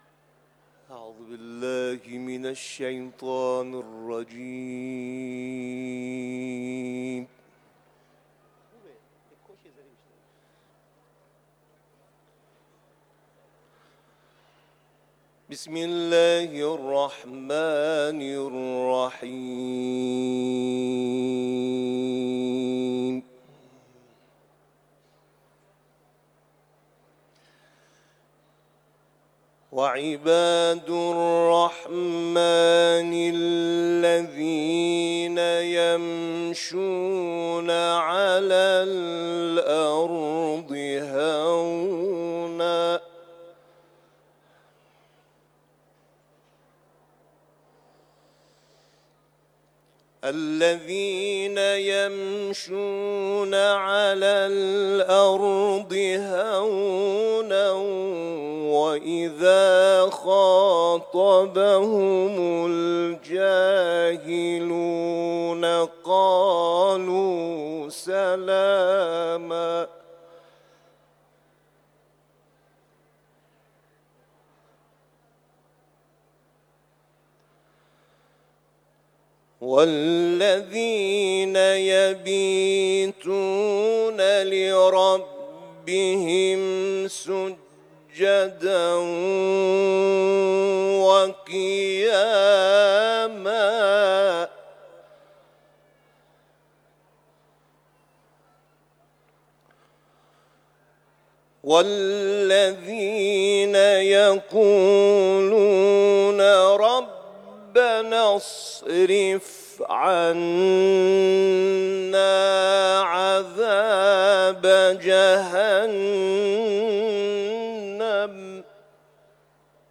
آیات 63 تا 69 سوره «فرقان» را در جوار بارگاه منور حضرت رضا(ع) تلاوت کرده است.
تلاوت